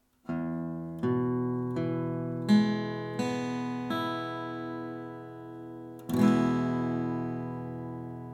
Hier lernst du, wie du den E-Dur-Akkord als offenen Akkord und als Barré-Griff auf der  A-Saite spielst.
E-Dur (Offen)
E-Dur.mp3